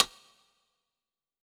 Ball Rim.wav